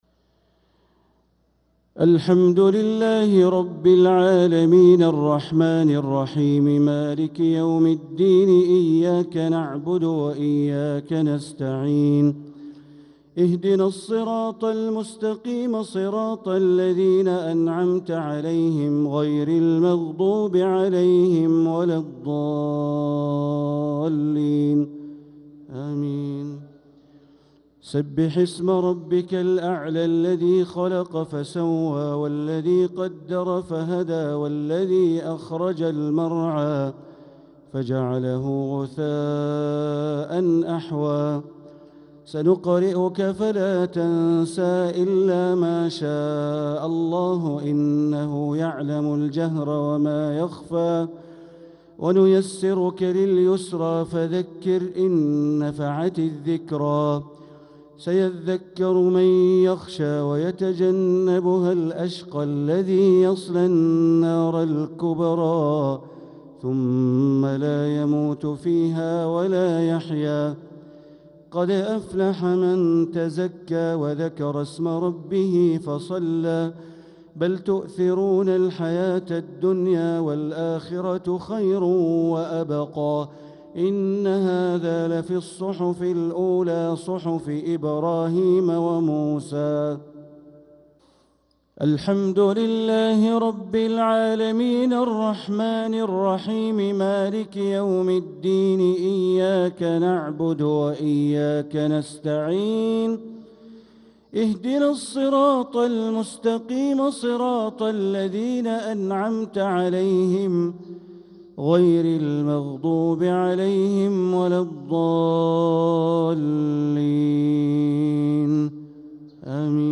تلاوة لسورتي الأعلى و الغاشية | صلاة الجمعة 1-4-1446هـ > 1446هـ > الفروض - تلاوات بندر بليلة